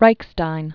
(rīkstīn, rīshtīn), Tadeus 1897-1996.